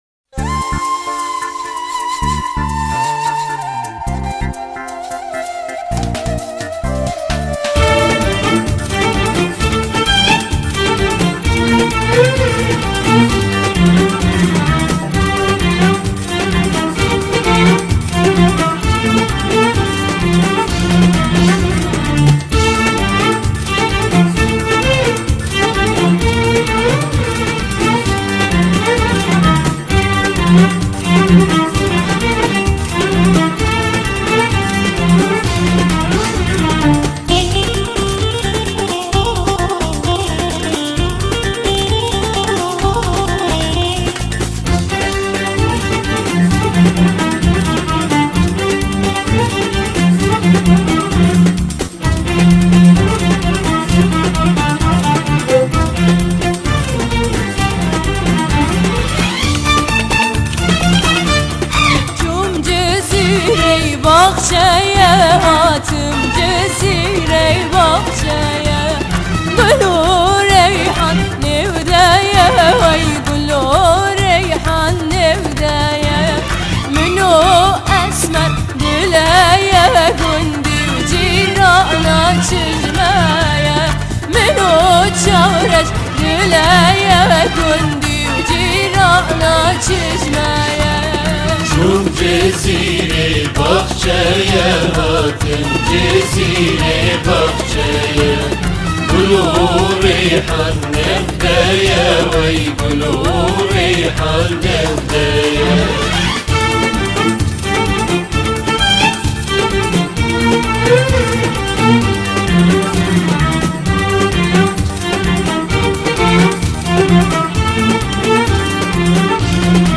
آهنگ کردی پاپ